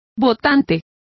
Complete with pronunciation of the translation of voters.